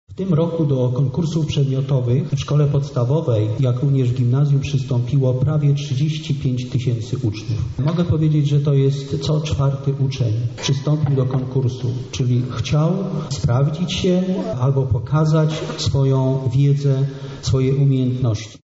W naszych konkursach wzięło udział wiele osób – mówi Krzysztof Babicz, lubelski Kurator Oświaty